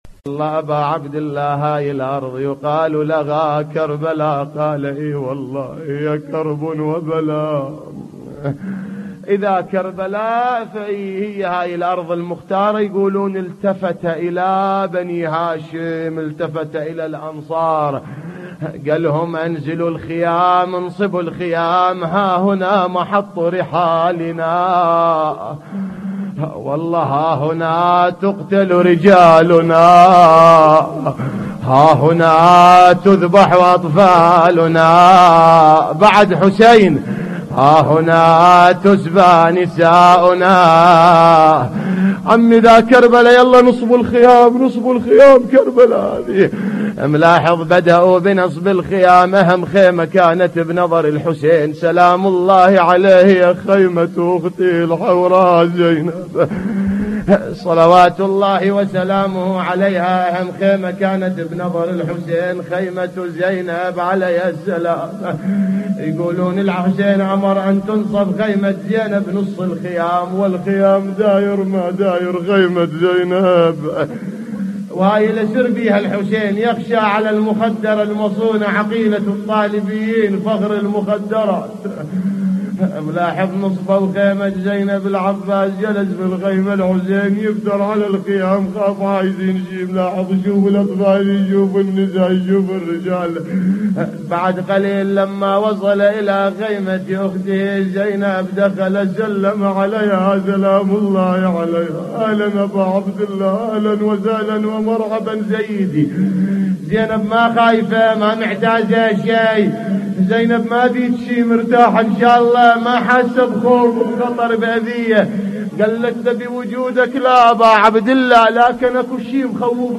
نواعي حسينية 9